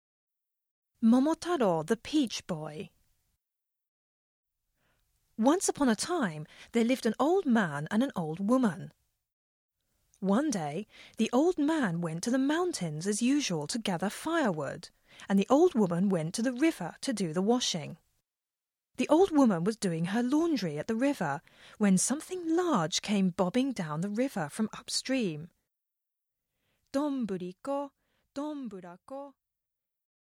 [オーディオブック] 多言語むかしばなし 桃太郎（4か国語版）
NHKデジタルラジオ「多言語むかしばなし」を再編集し、オーディオブック化しました。